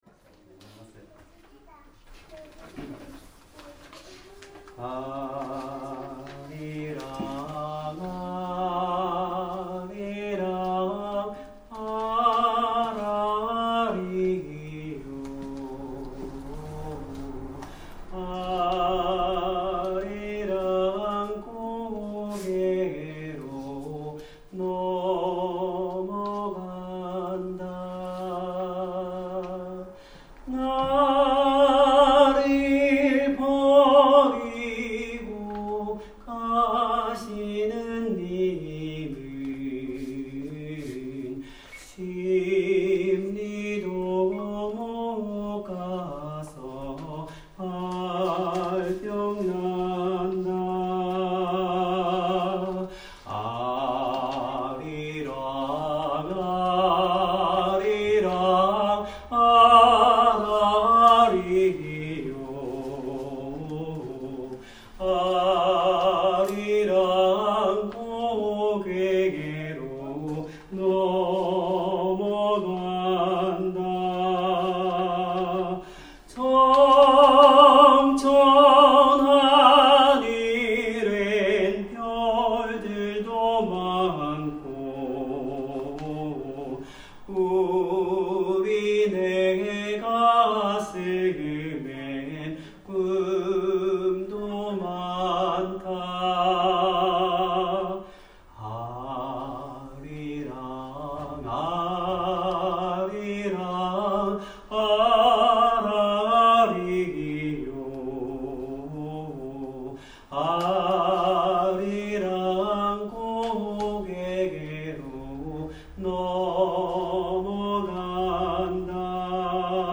みんなのコンサート より　Everyone's Concert
Place: Kashiwa Shalom Church